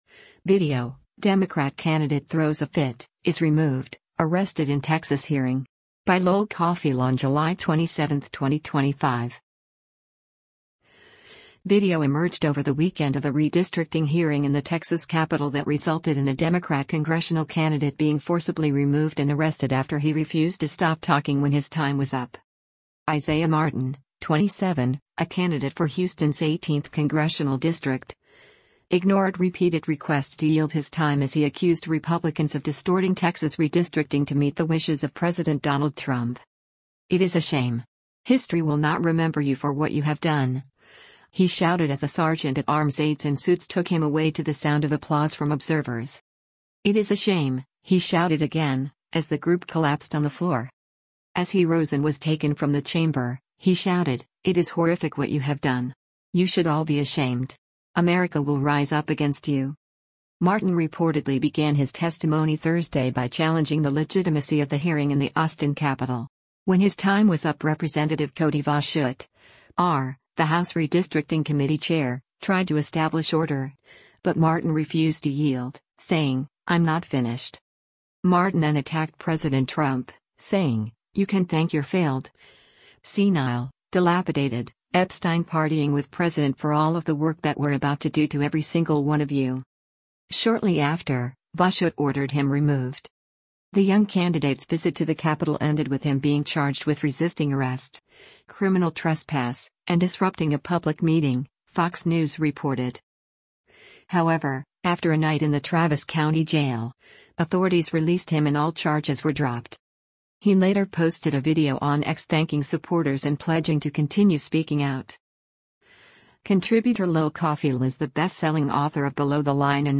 Video emerged over the weekend of a redistricting hearing in the Texas Capitol that resulted in a Democrat congressional candidate being forcibly removed and arrested after he refused to stop talking when his time was up.
“It is a shame!” he shouted again, as the group collapsed on the floor.